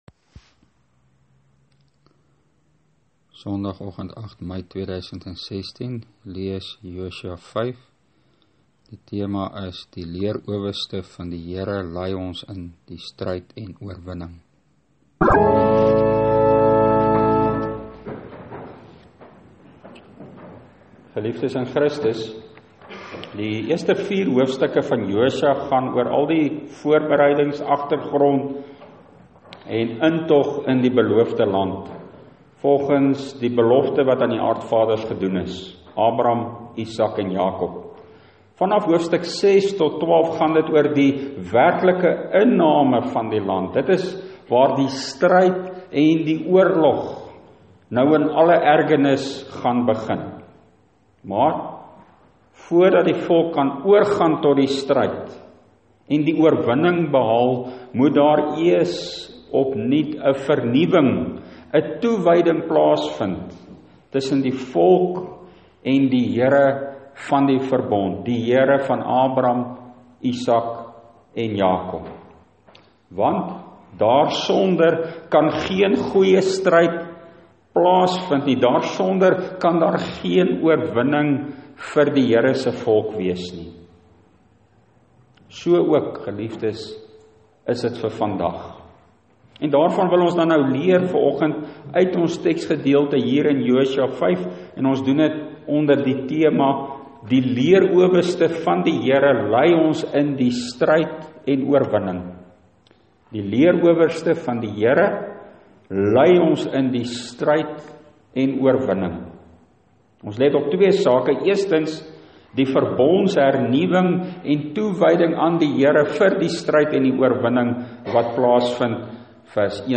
Preekopname (GK Carletonville, 2016-05-08):